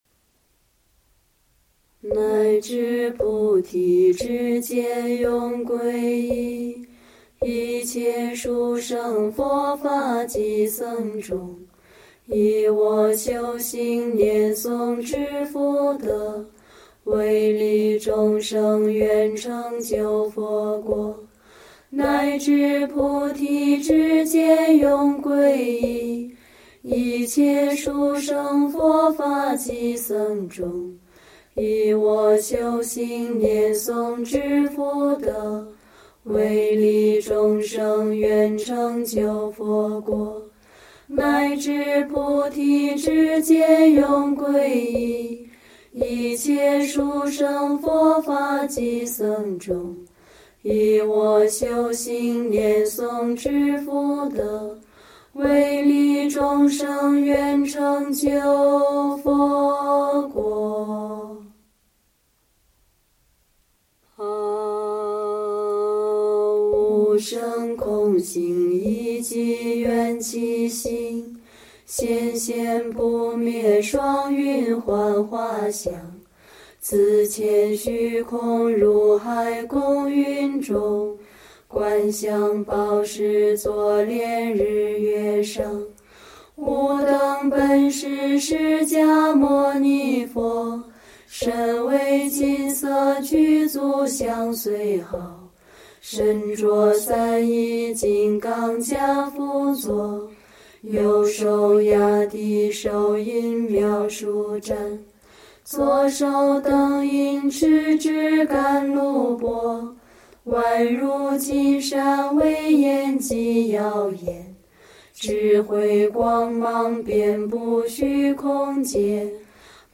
师领诵《释尊修法仪轨》
汉文念诵：